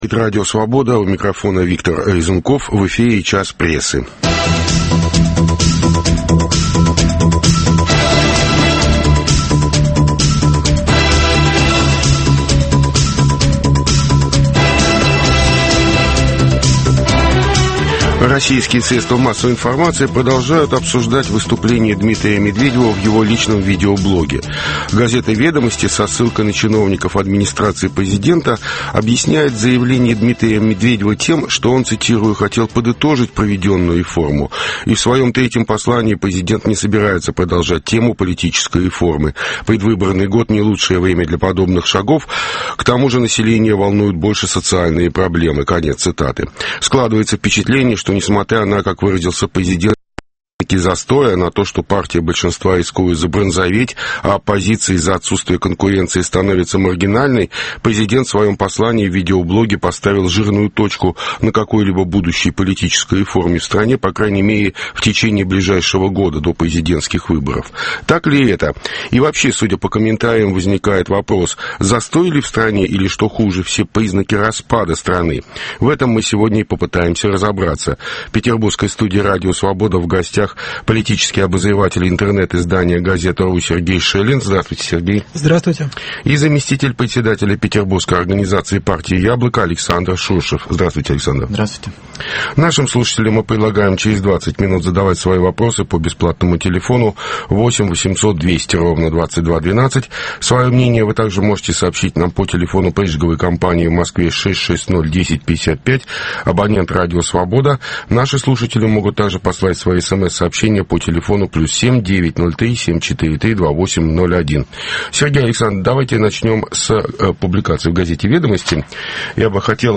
Застой или признаки распада государства? Как реагирует общество на последнее выступление Дмитрия Медведева? Обсуждают: политический обозреватель